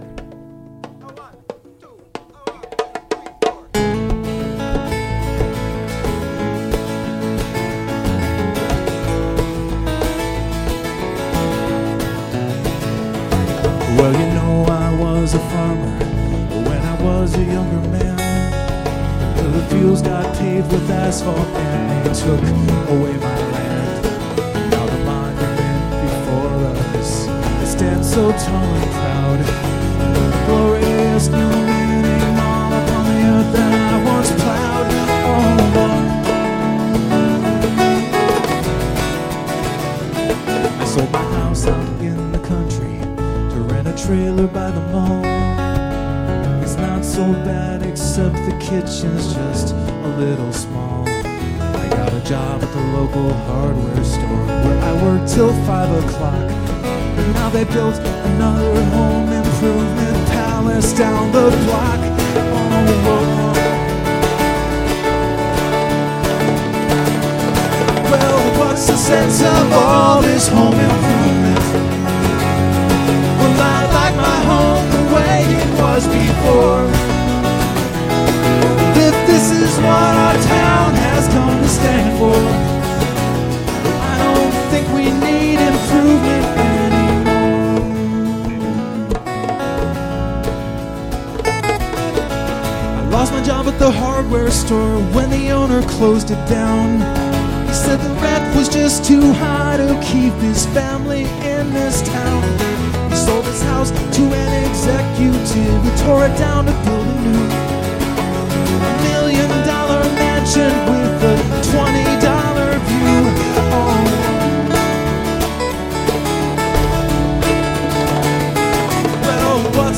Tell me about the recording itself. Here are some random MP3s from my live shows: